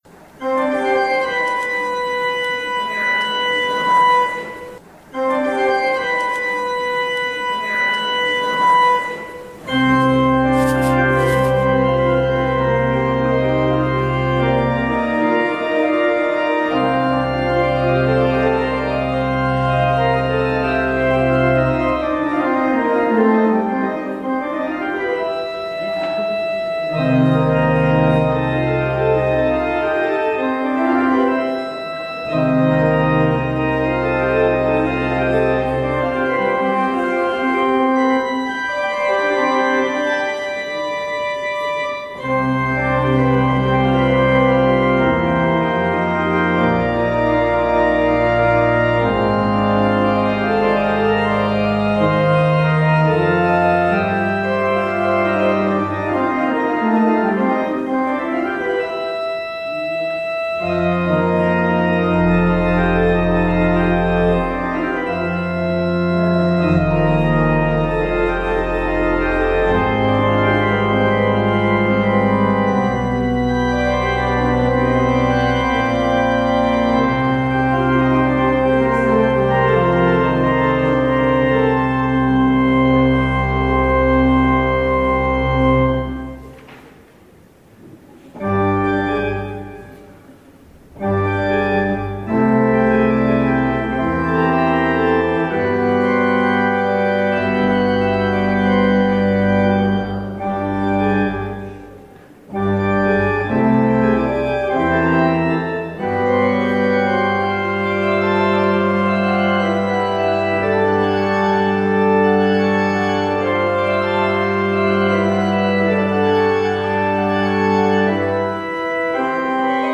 Audio recording of the 10am hybrid/streamed service